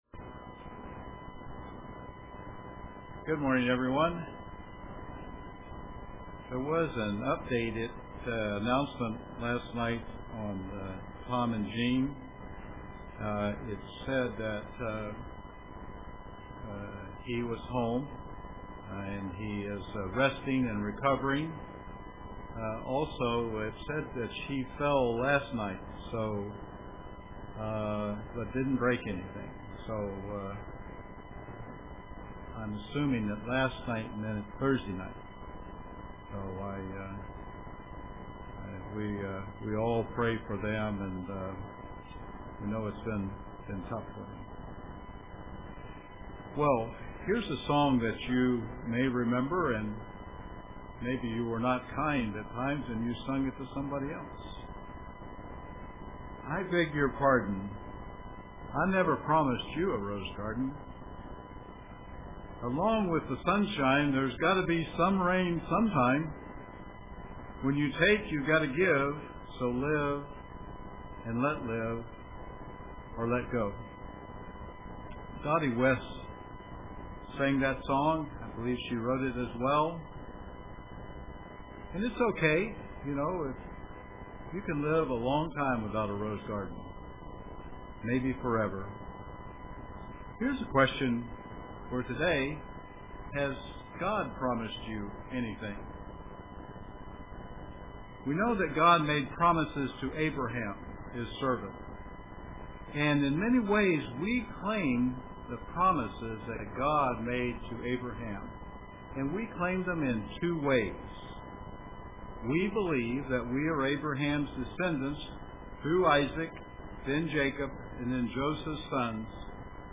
The Promises of God UCG Sermon Studying the bible?